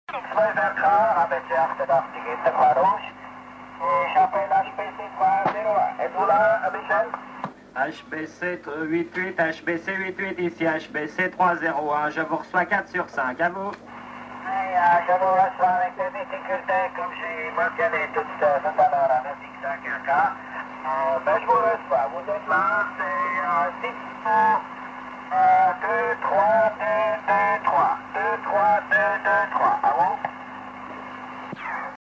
Trafic radio: Najran